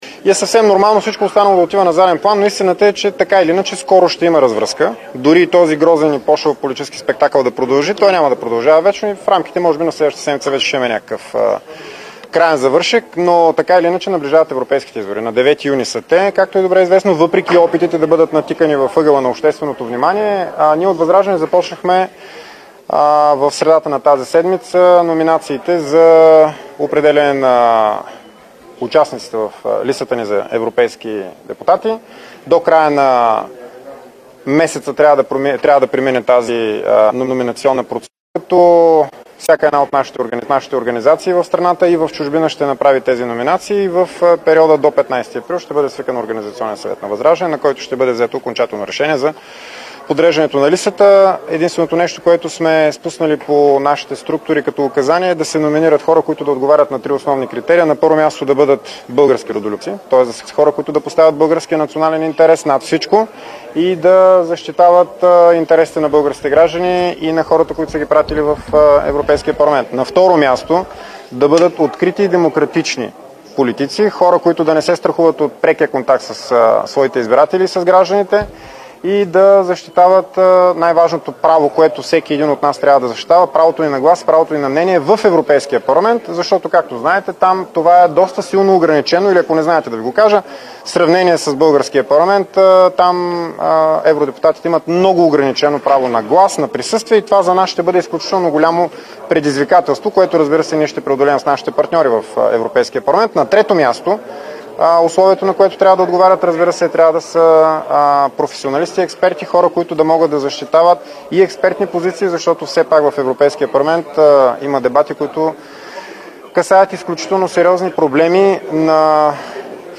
11.05 - Брифинг на Корнелия Нинова и Драгомир Стойнев за предложението на БСП за отлагане на либерализацията на пазара на ток за домакинствата.
Директно от мястото на събитието